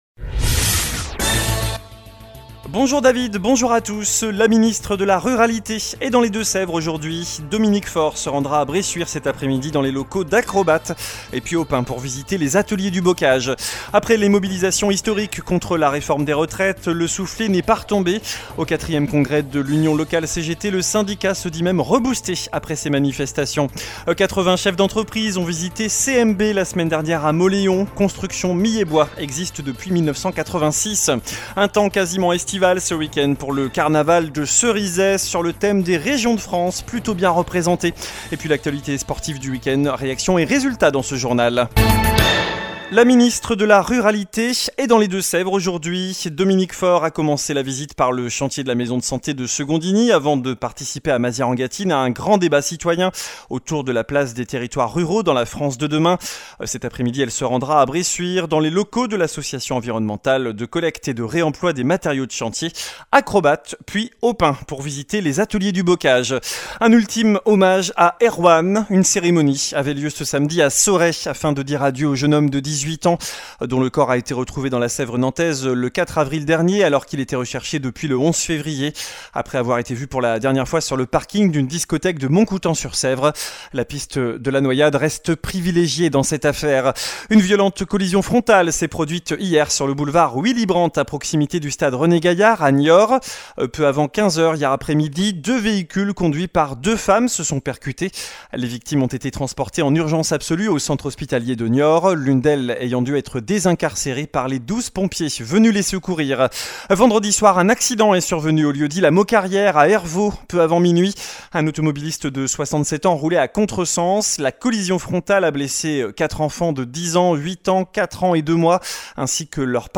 Journal du lundi 15 avril (midi)